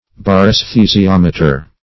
Search Result for " baraesthesiometer" : The Collaborative International Dictionary of English v.0.48: Baraesthesiometer \Bar`[ae]s*the`si*om"e*ter\, Baresthesiometer \Bar`es*the`si*om"e*ter\, n. [Gr.